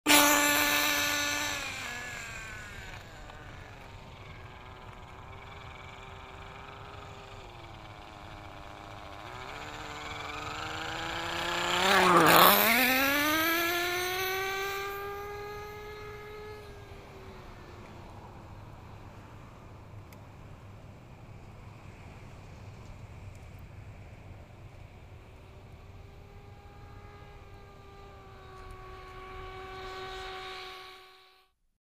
Звуки детских игрушек
Детская радиоуправляемая машинка